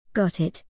▶アメリカ英語 (tのflapping有り)
▶イギリス英語 (tのflapping無し)